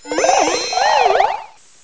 pokeemerald / sound / direct_sound_samples / cries / uncomp_eldegoss.aif